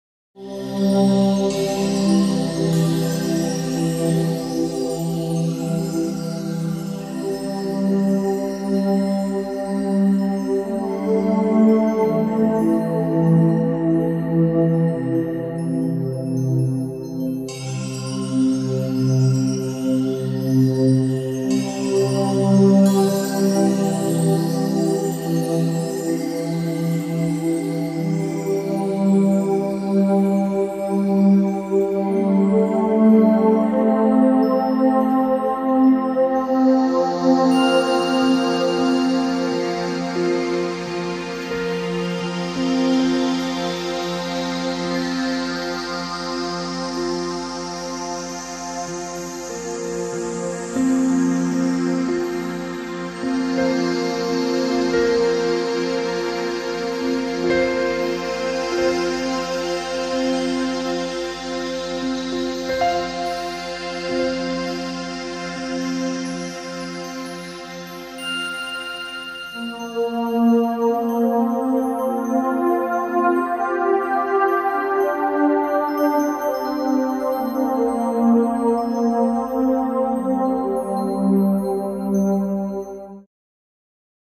reiki music
healing-reiki-music-part-2-reiki-music2.mp3